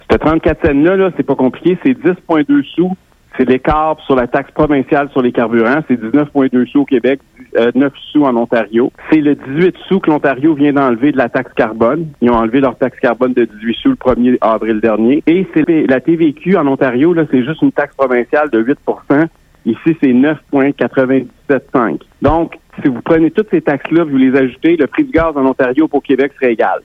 L’abolition du prix plancher de l’essence ne changera rien pour le consommateur selon le chef du Parti conservateur du Québec, Éric Duhaime. Au micro de Radio Beauce, le chef du PCQ, estime qu’il s’agit d’une diversion du gouvernement Legault, alors que l’essence est 0,34 cents plus cher au Québec qu’en Ontario.